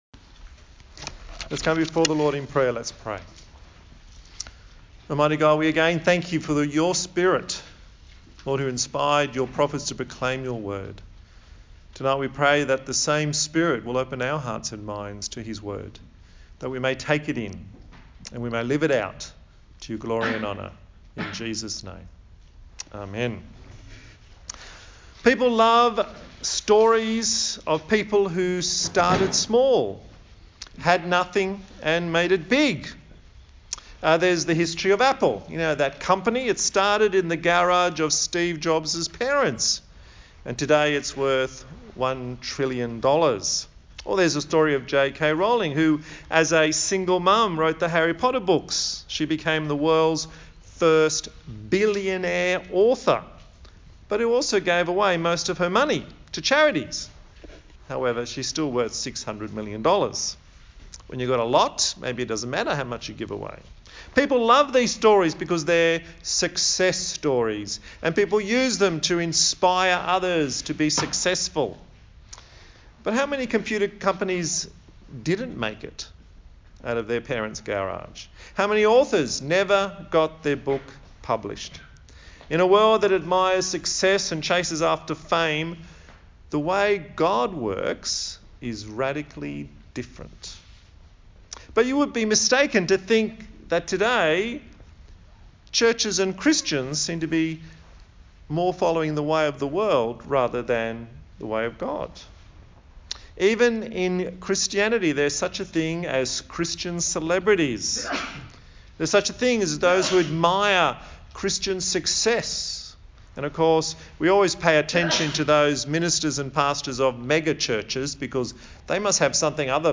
A sermon in the series on the book of Zechariah